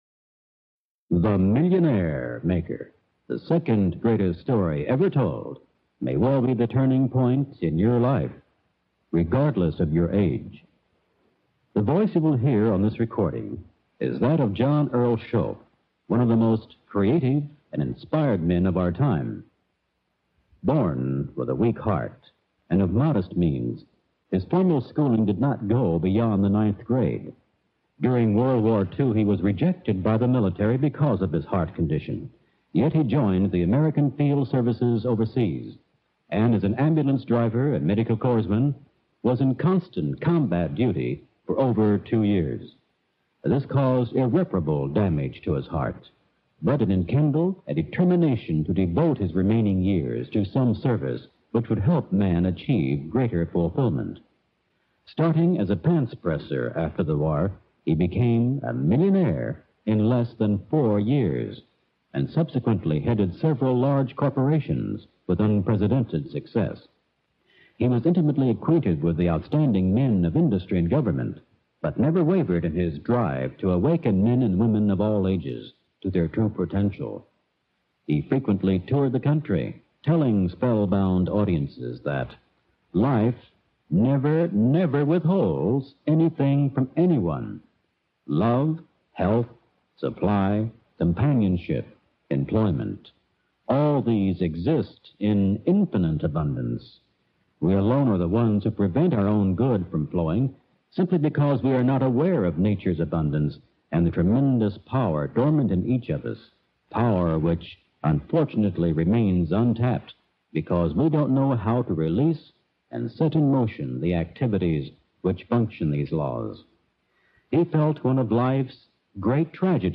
(Transcribed from orginal vinyl recording 1962)